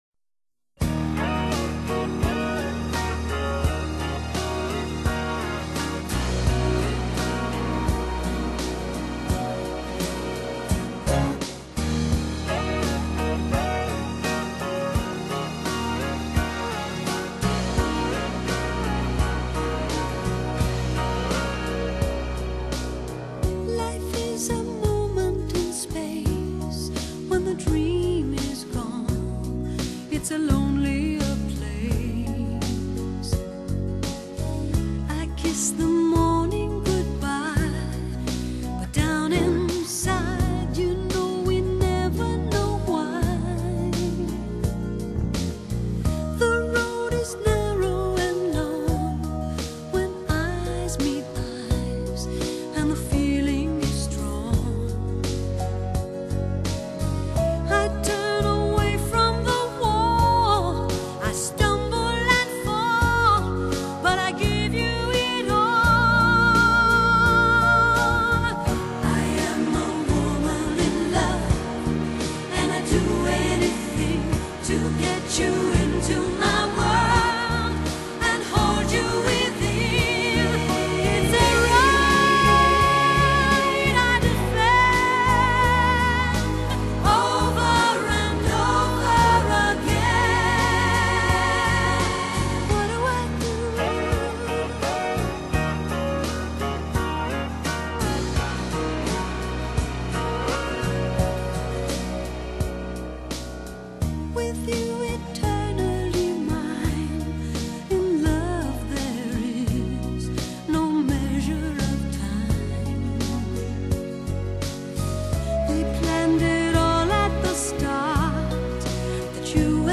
Vocal, Pop, Evergreen